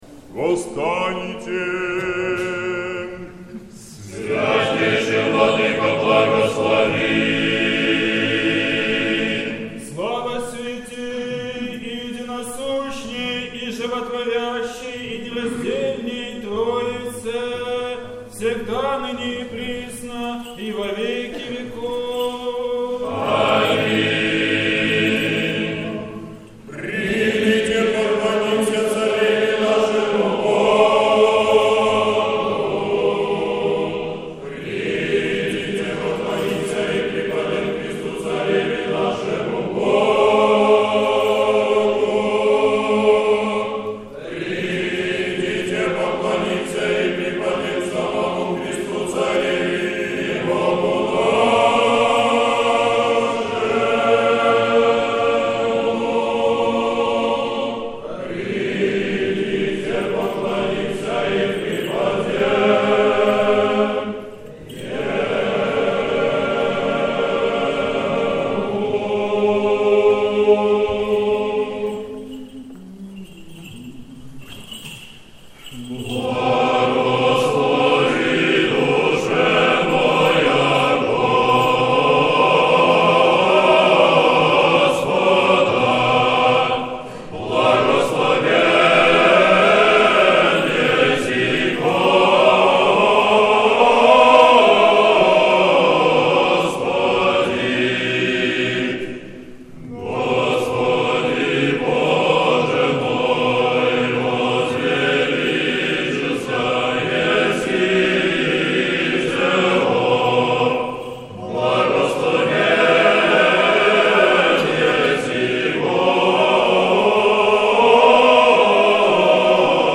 Всенощное бдение в Сретенском монастыре накануне Недели 22-й по Пятидесятнице
Великая вечерня, утреня. Хор Сретенского монастыря.